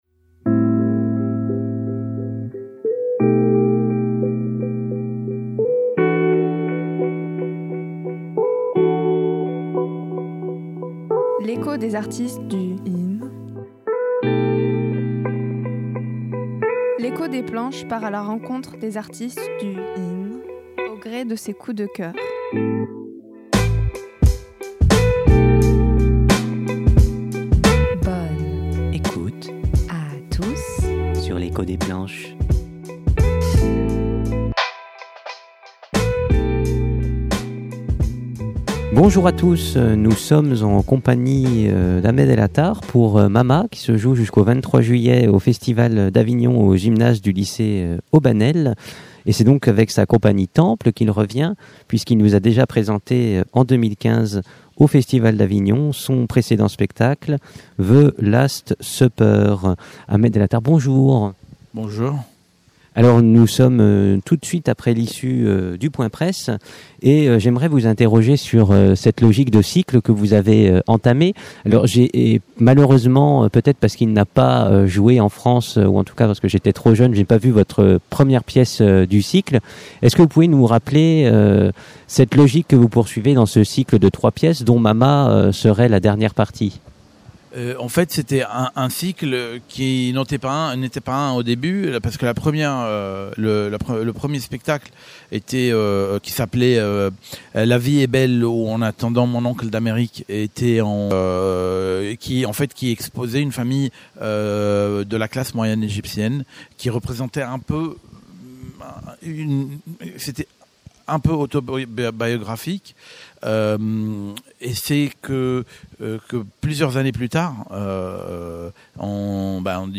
l’entretien